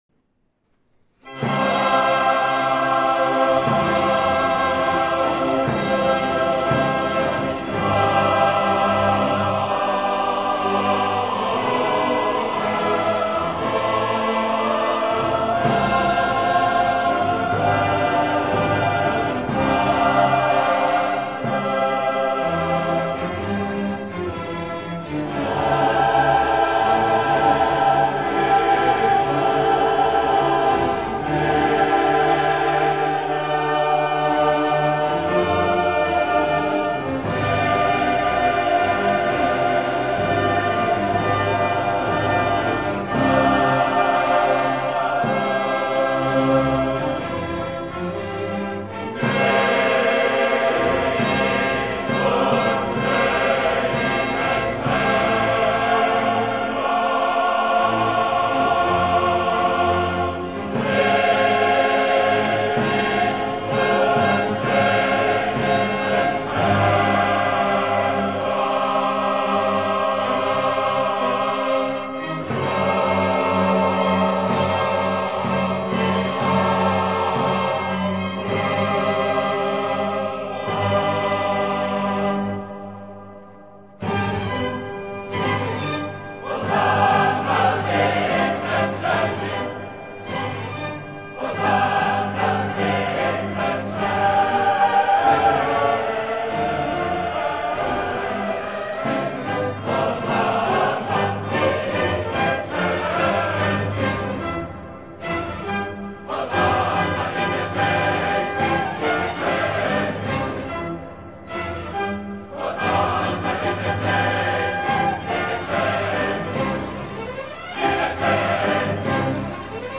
Mass in C major